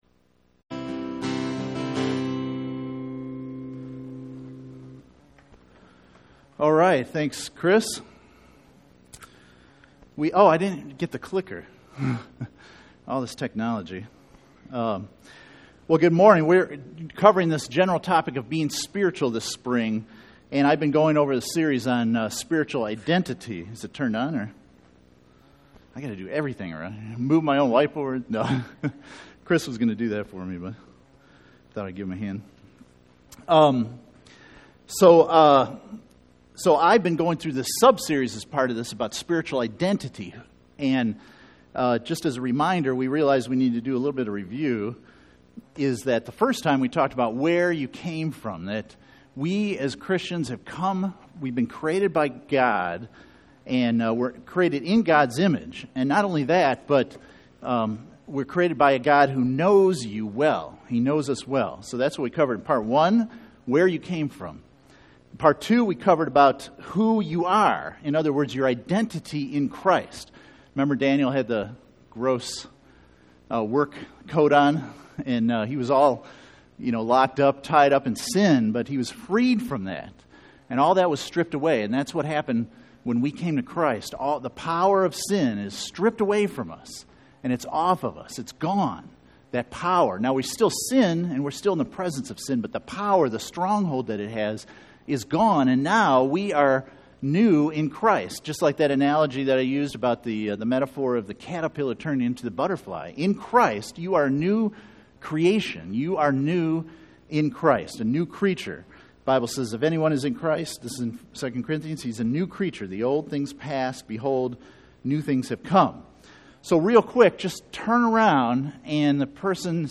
Spiritual Identity Service Type: Sunday Morning %todo_render% « Spiritual Well Being 3 Spiritual Identity